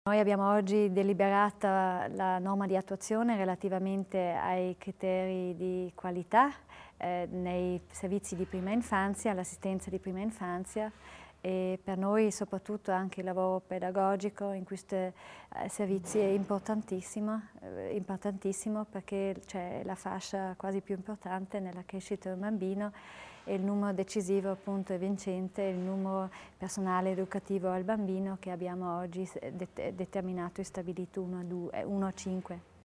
L'Assessore Deeg spiega l'importanza della qualità nei servizi per l'infanzia